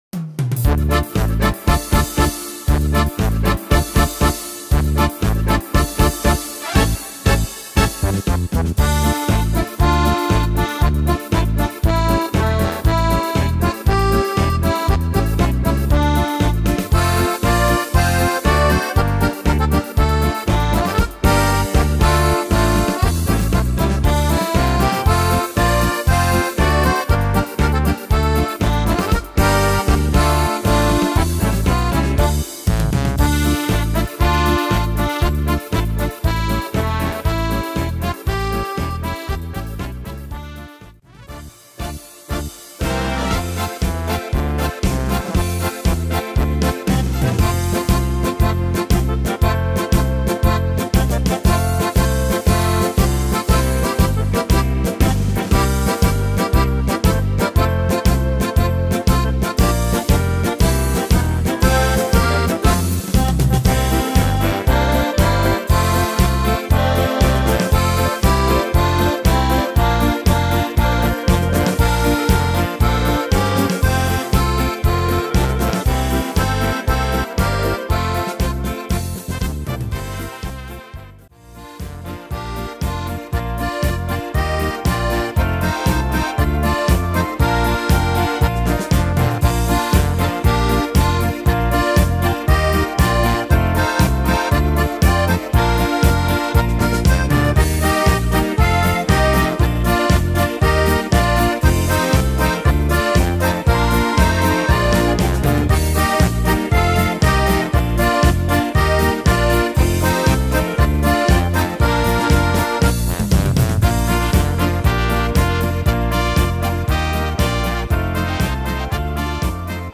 Tempo: 236 / Tonart: F-Dur